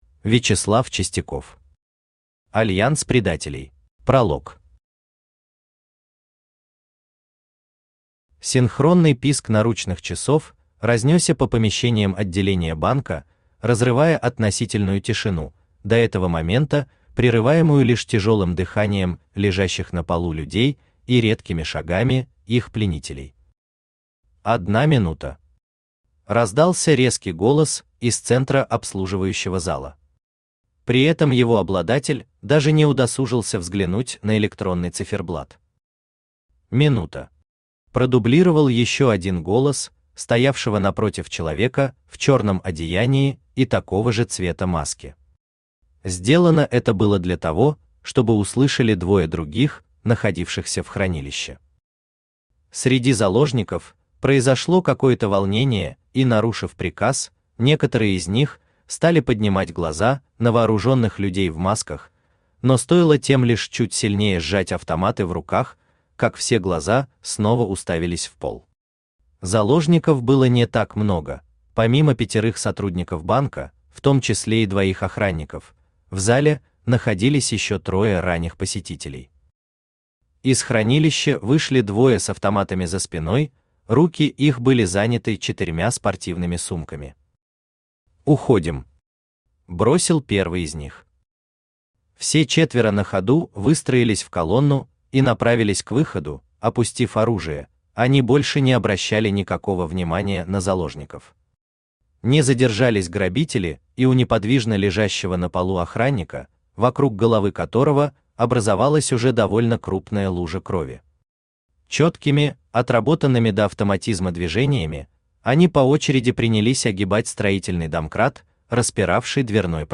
Аудиокнига Альянс предателей | Библиотека аудиокниг
Aудиокнига Альянс предателей Автор Вячеслав Сергеевич Чистяков Читает аудиокнигу Авточтец ЛитРес.